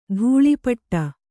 ♪ dhūḷi paṭṭa